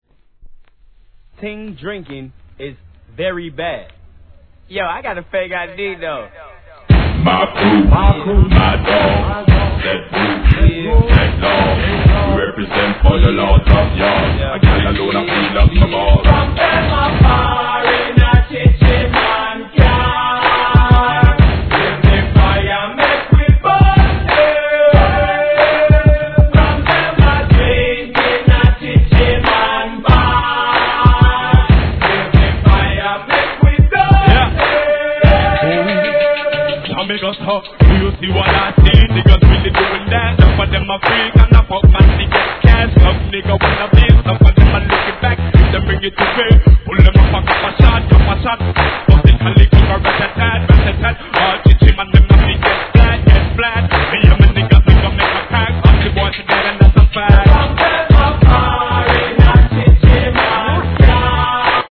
REGGAE X HIP HOPブレンドシリーズ第5弾!